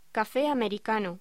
Locución: Café americano